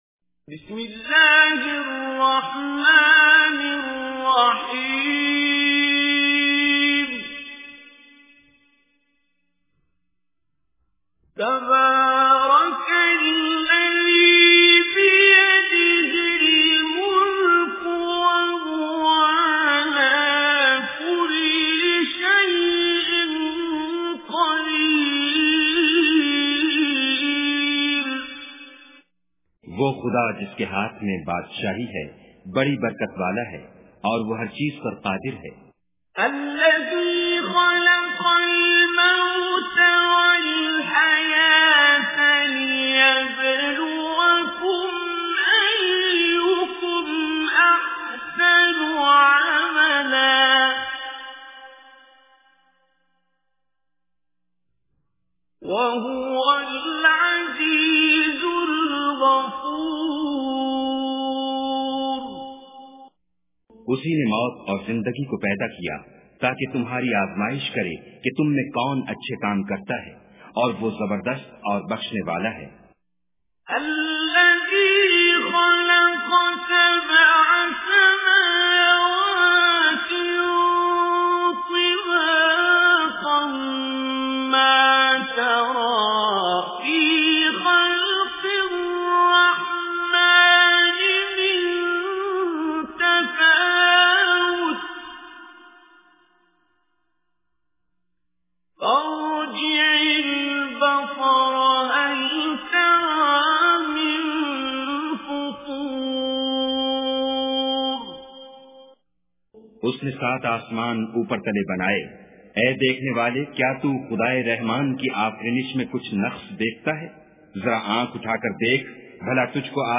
Surah Mulk Recitation with Urdu Translation
Listen online and download mp3 tilawat / recitation of Surah Mulk in the voice of Qari Abdul Basit As Samad.